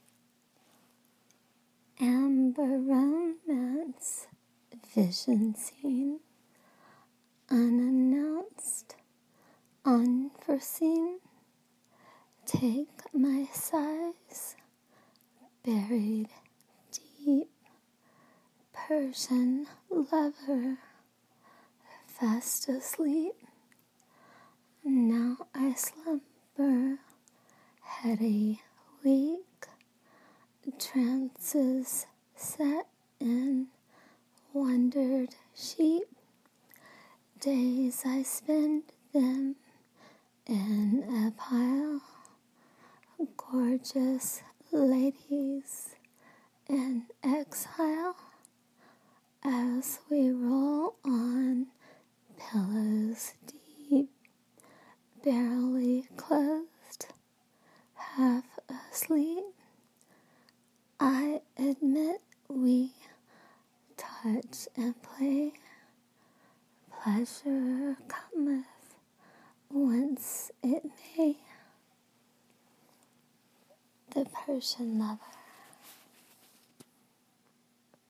Spoken-word poetry